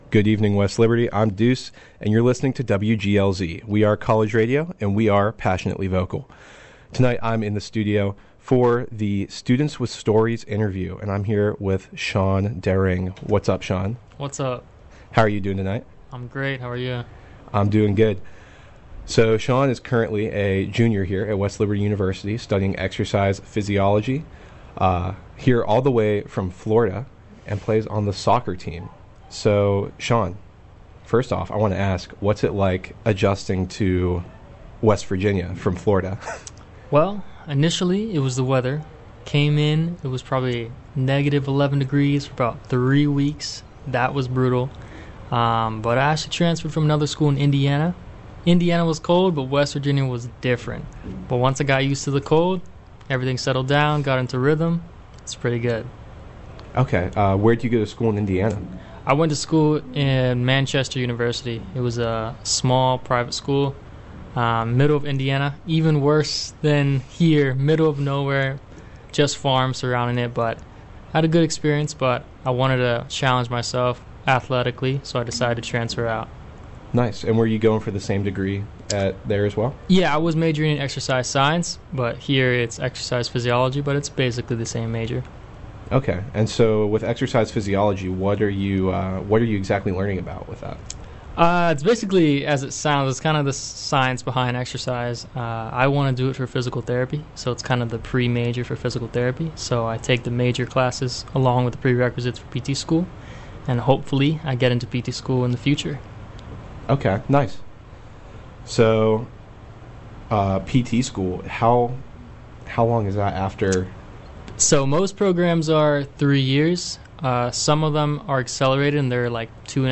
in the WGLZ studio
Interview